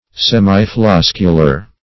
\Sem`i*flos"cu*lar\